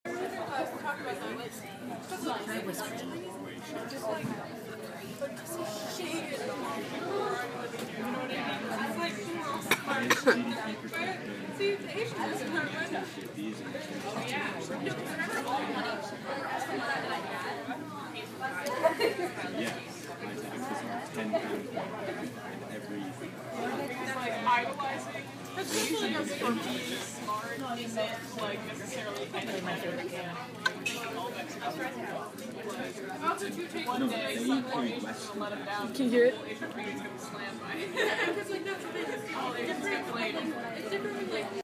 Location & Time: Bits & Bytes, Thursday 3/3/16, 2:30 PM
Sounds: Talking, coughing, walking